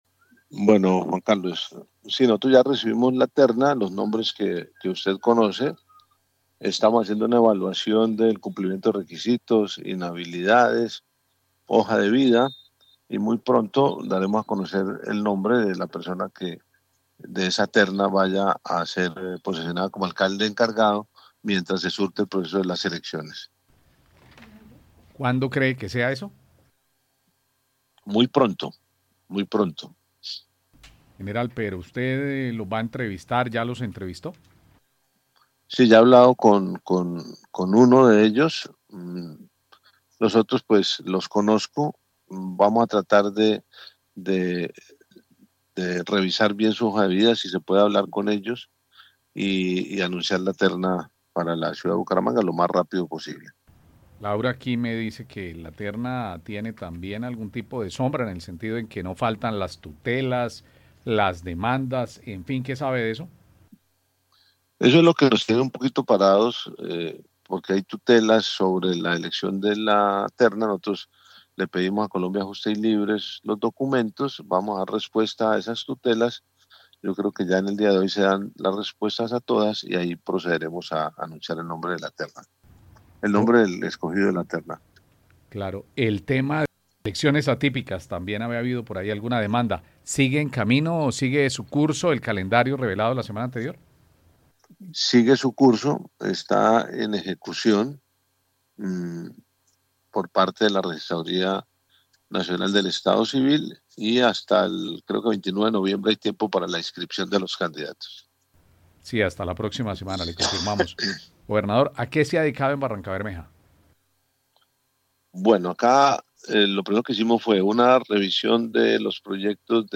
Gobernador de Santander habla de la escogencia del alcalde de Bucaramanga